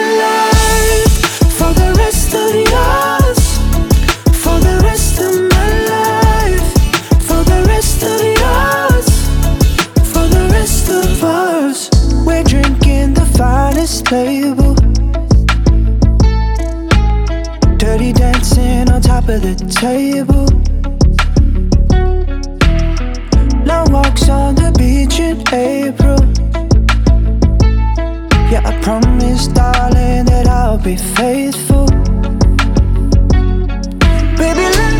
Скачать припев
это мгновенный заряд позитива.
2018-04-12 Жанр: Поп музыка Длительность